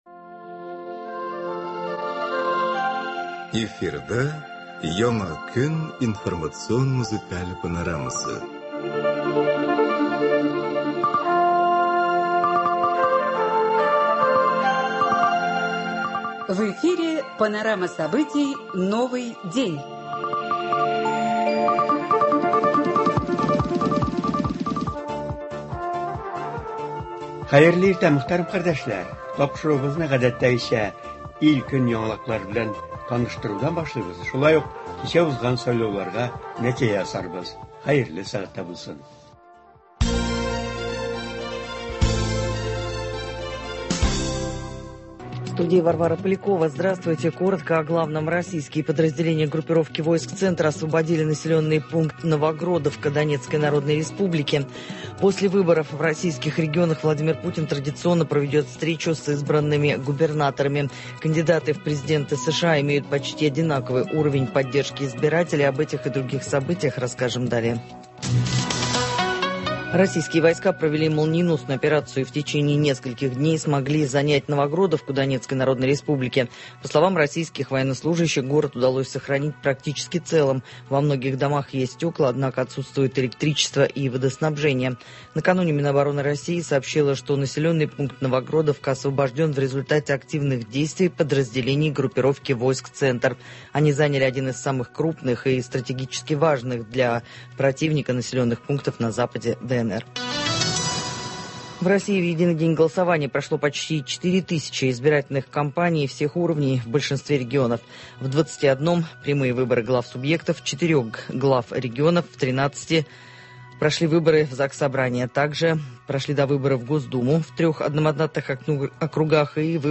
Туры эфирда кичә үткәрелгән Татарстан Дәүләт Советына депутатлар сайлауга нәтиҗәләр ясыйбыз.
В прямом эфире подводим итоги состоявшихся вчера в Татарстане выборов депутатов Госсовета.